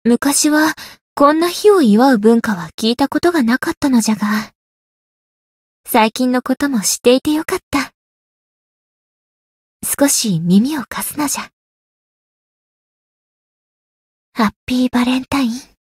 灵魂潮汐-蕖灵-情人节（相伴语音）.ogg